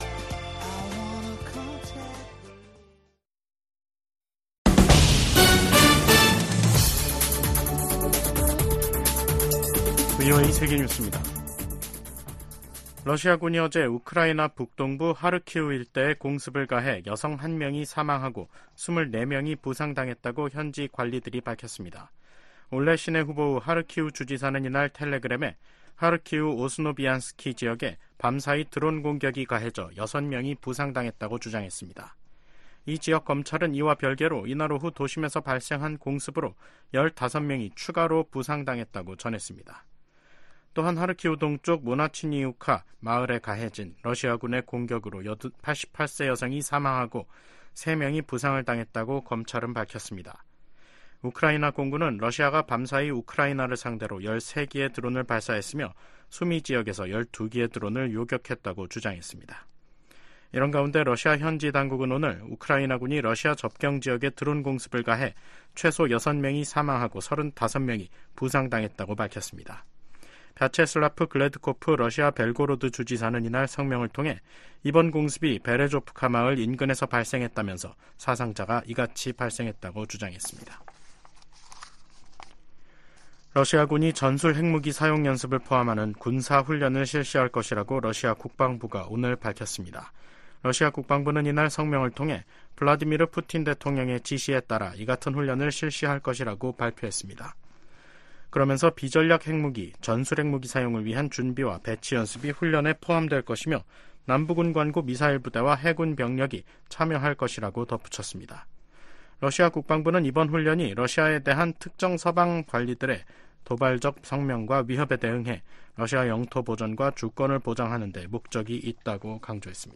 VOA 한국어 간판 뉴스 프로그램 '뉴스 투데이', 2024년 5월 6일 3부 방송입니다. 미국과 일본, 호주 국방장관들이 북러 군사협력 심화와 북한의 반복적인 미사일 발사를 강력히 규탄했습니다. 북한이 아무런 댓가없이 러시아에 무기를 지원하는 것은 아니라고 미국 관리가 말했습니다. 북러 무기 거래가 명백한 유엔 안보리 결의 위반이라는 점도 지적했습니다.